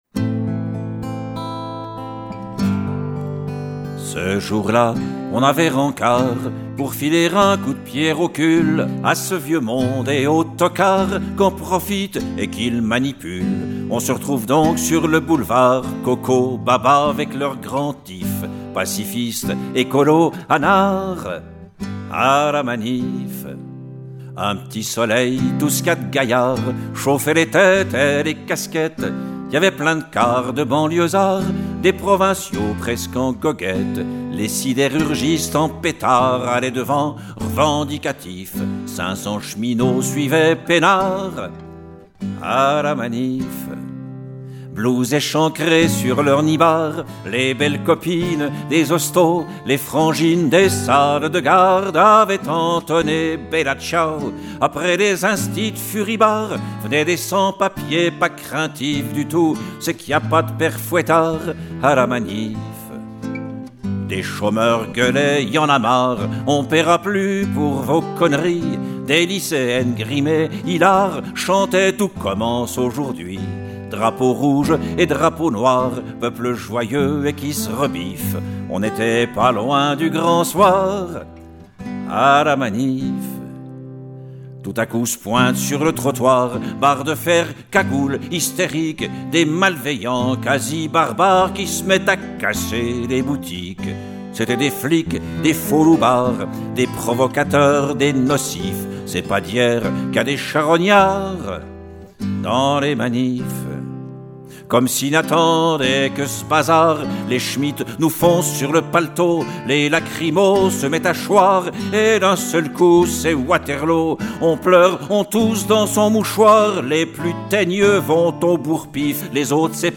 Voix, guitares, instrument midi